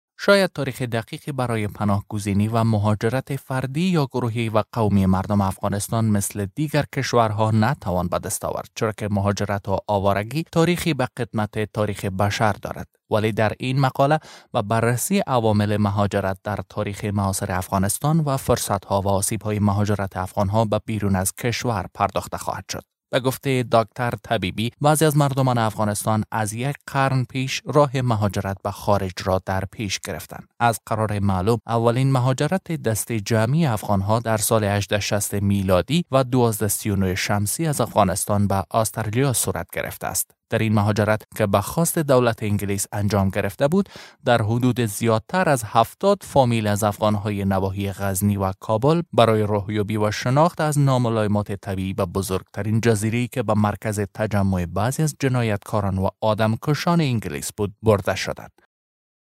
Male
Adult
Narration